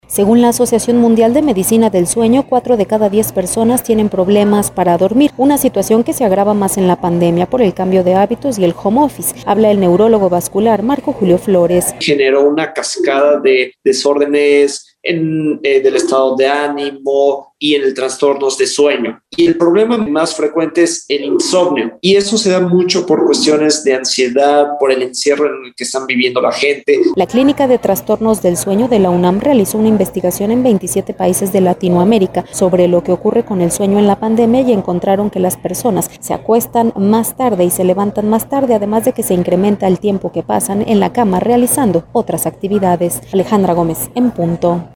Según la asociación mundial de Medicina del Sueño, cuatro de cada 10 personas tienen problemas para dormir, una situación que se agrava más en la pandemia, por el cambio de hábitos y el home office. Habla el neurólogo vascular